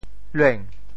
“煖”字用潮州话怎么说？
lueng2.mp3